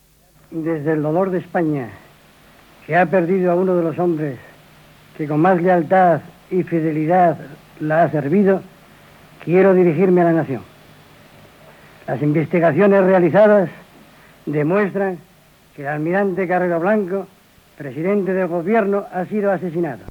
El vicepresident del Govern espanyol Torcuato Fernández-Miranda confirma l'assassinat del president del Goven, almirall Luis Carrero Blanco (per la banda terrorista ETA)
Informatiu